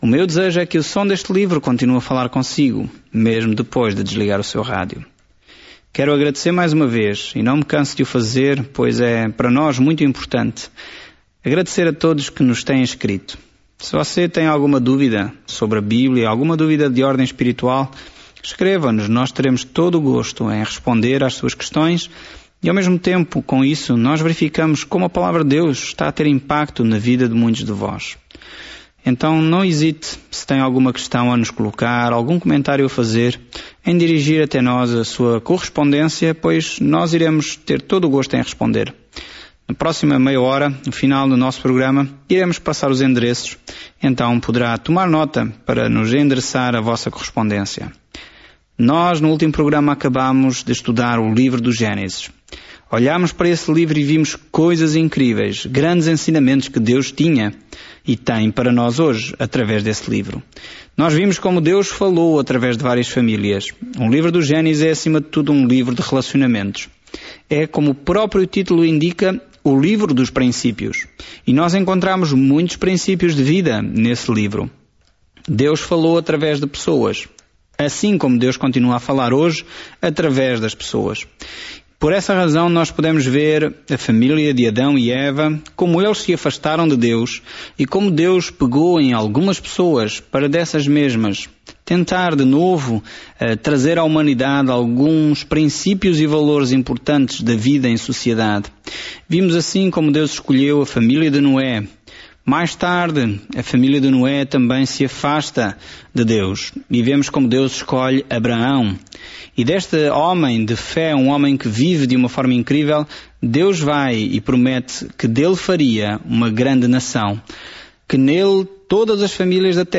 Escritura MATEUS 1:1 Iniciar este Plano Dia 2 Sobre este plano Mateus prova aos leitores judeus as boas novas de que Jesus é o Messias, mostrando como Sua vida e ministério cumpriram a profecia do Antigo Testamento. Viaje diariamente por Mateus enquanto ouve o estudo em áudio e lê versículos selecionados da palavra de Deus.